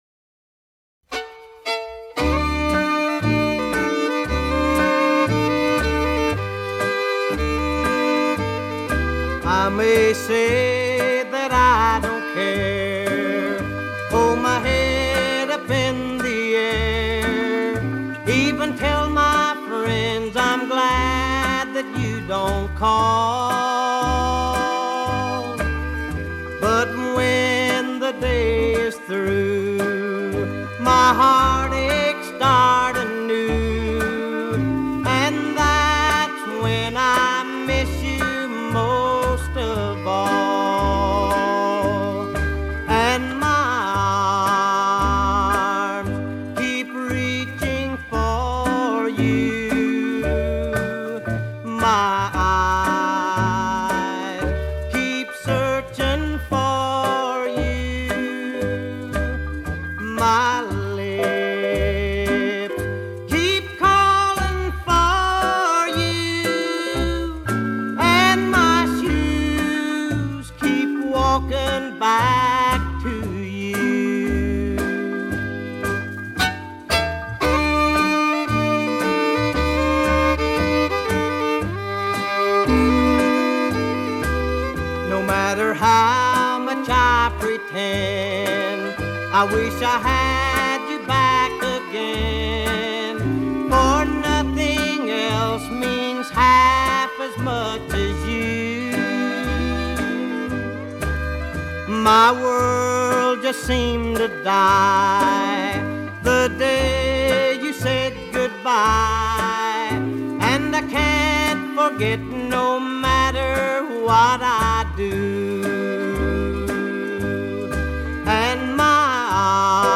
Country music of the 50s